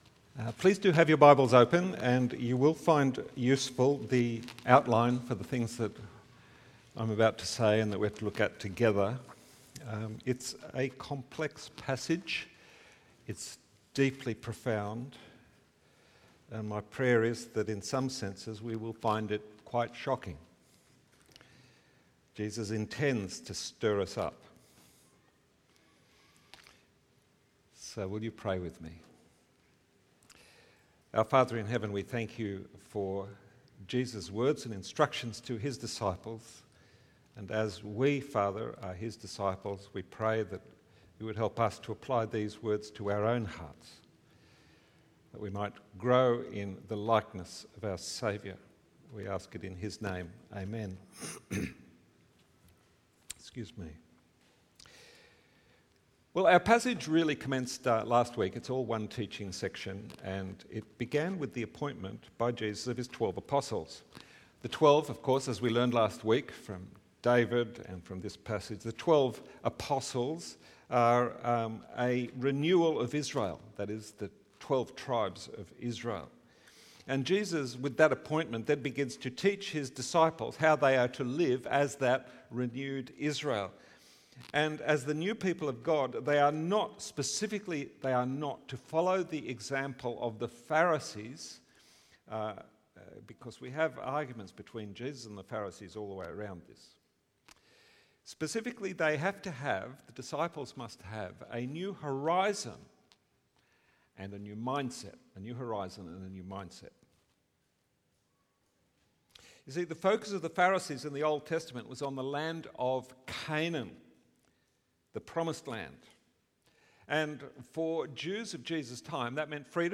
Sermons | St Johns Anglican Cathedral Parramatta
Watch the full service on YouTube or listen to the sermon audio only.